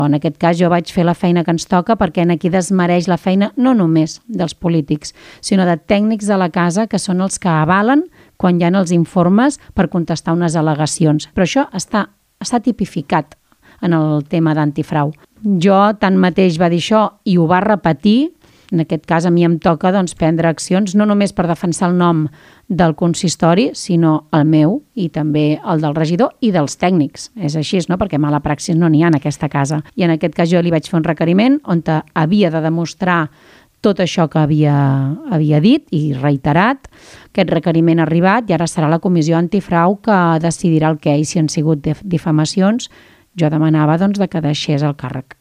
L'alcaldessa d'Argentona, Montse Capdevila (Tots per Argentona), ha revelat a l'entrevista de gener a Ràdio Argentona, que ha presentat un requeriment per dirimir si les acusacions que va manifestar Isidre Viñas (Unitat) al ple de gener incorren en alguna il·legalitat.